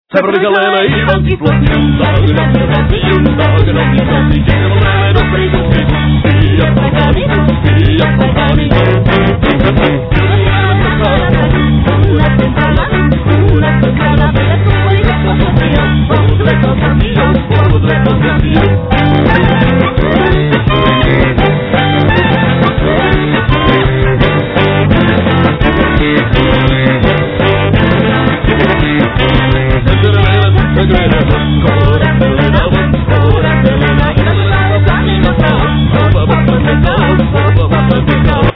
Klarinet, Alt saxophone
Accordion
Vocals
Guitar, Balalaika
Violin